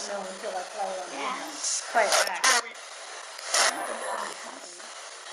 The recorders did pick up some strange sounds.
There is a singsong voice in the background.
There is a loud voice that breaks in.
The frequency scanner was still scanning frequencies at a slow rate but didn’t stop.
There is singing in the background.
There is a voice that breaks in.